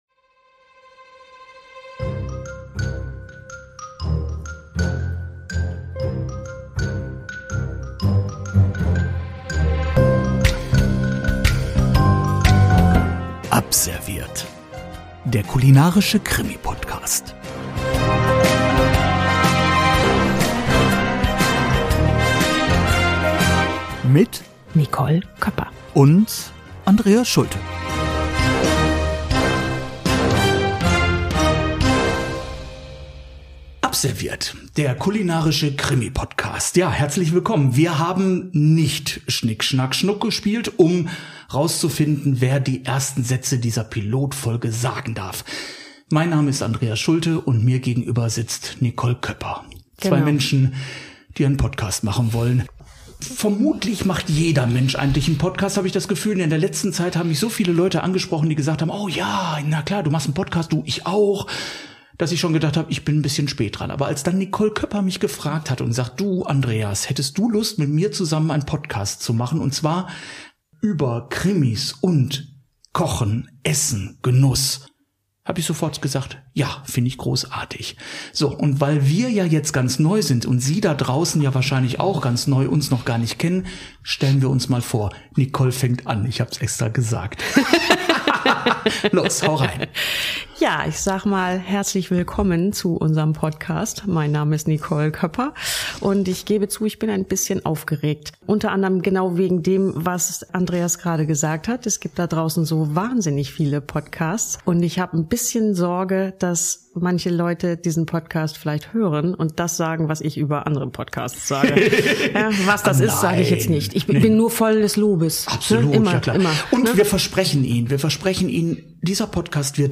Köchin trifft Krimiautor.